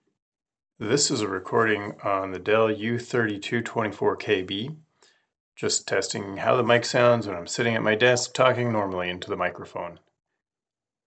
For those wondering about the microphone quality, I compared the U3224KB, Studio Display, and my RE20 dynamic microphone.
The Dell sounds a bit tinny and less clear than the ASD's mic, but acceptable and intelligible.